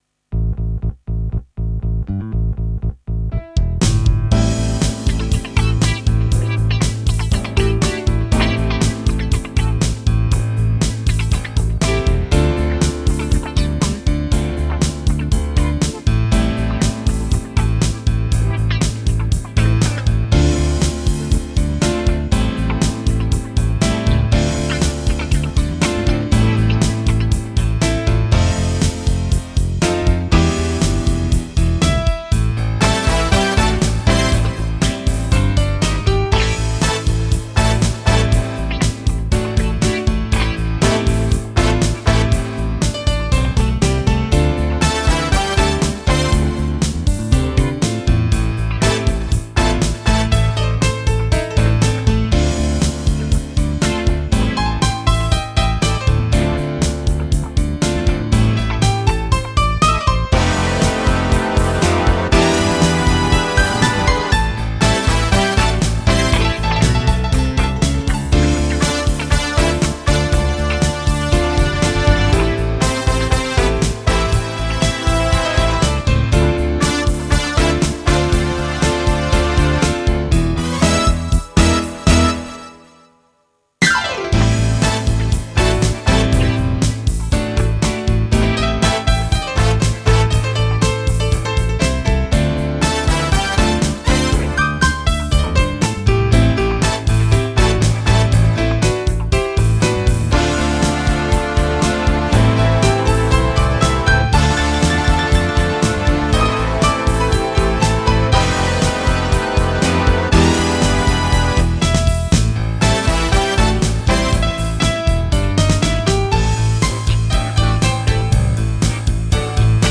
KORG Pa4X mp3 user own voice